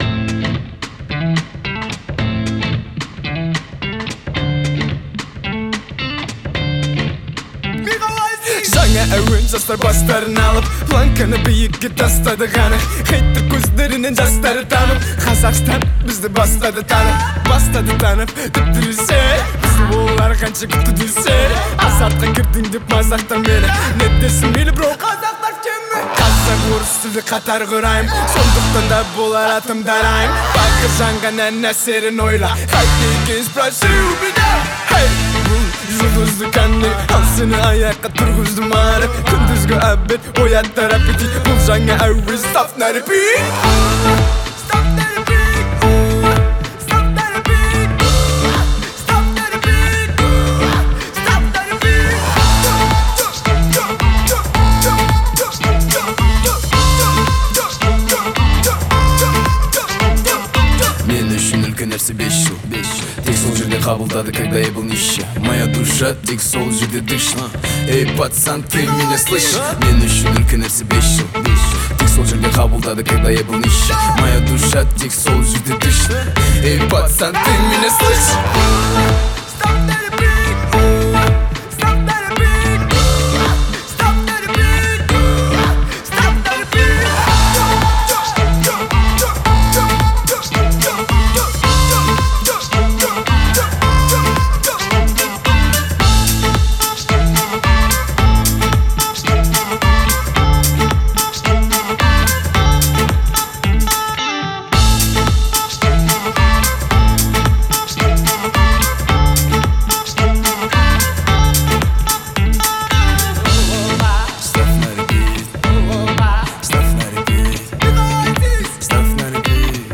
это энергичная песня в жанре рэп с элементами хип-хопа